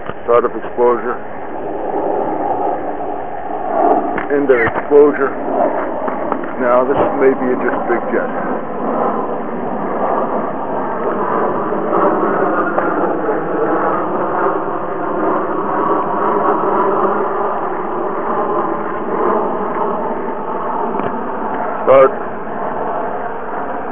Look at the sound profile (sound spectrogram) and compare it with that of a DC-9.
However, upon further analysis the sound appears to be synthetic, or manufactured (like that of a midi, which is comprised of many individual electronic tones), and unlike the sounds of conventional jets.
ft overhead